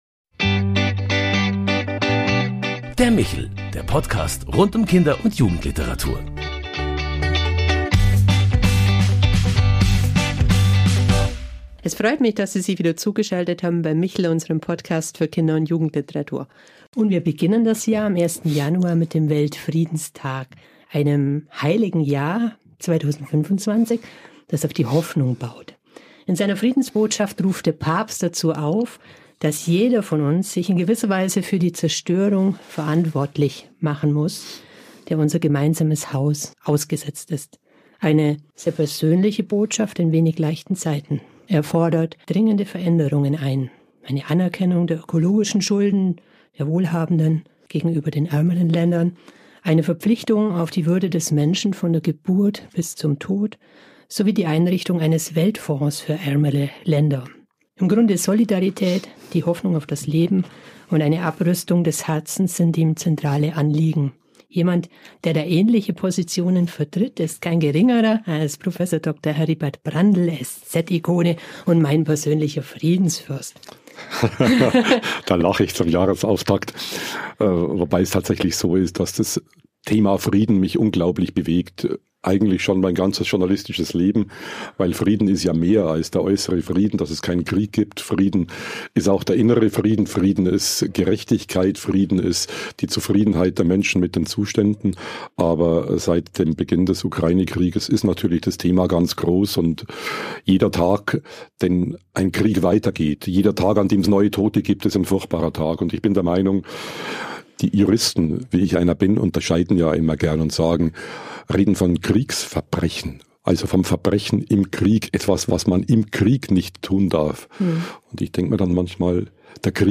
Der Michel – Der Podcast für Kinder und Jugendliteratur - Frieden und die Kraft der Worte – Ein Gespräch mit Heribert Prantl